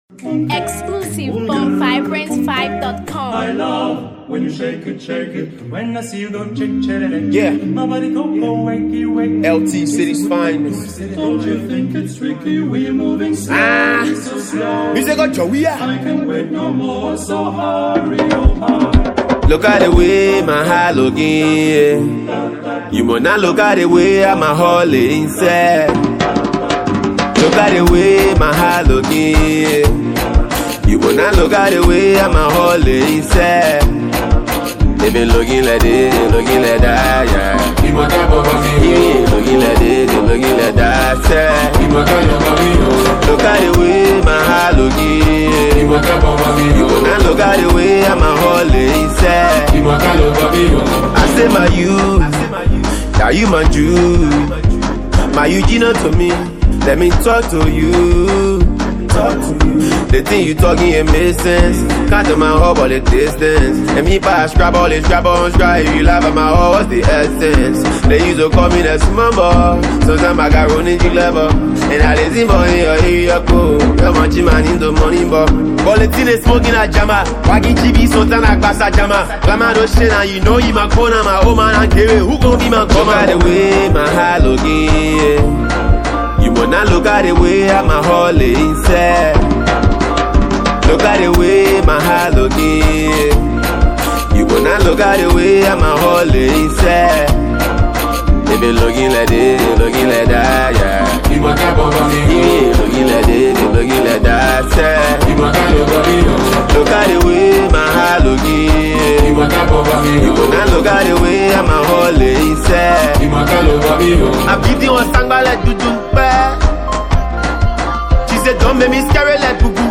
a vibrant mash-up of styles
and Afro-fusion sounds” for a fresh, danceable vibe